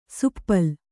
♪ suppal